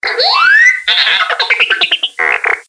Звуки дроида R2D2 из звёздных войн в mp3 формате
5. Смех робота